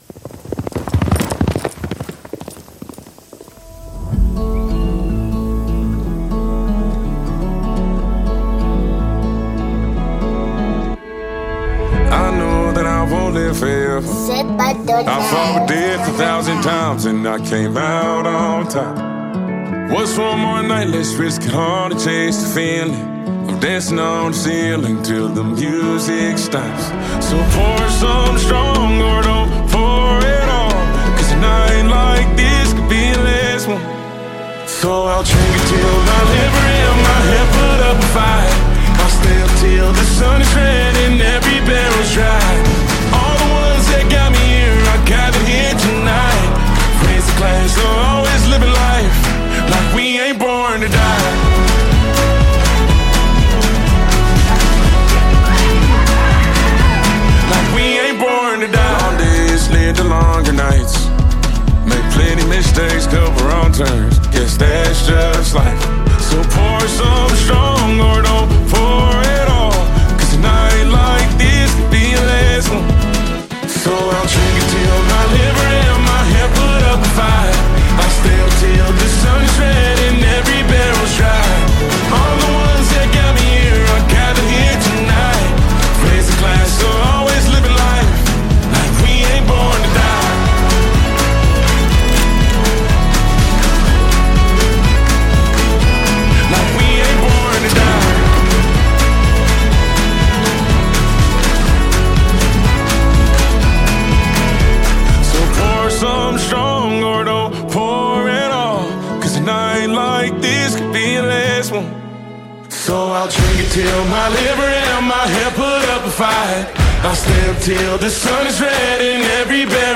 The beat is infectious and the melody is catchy.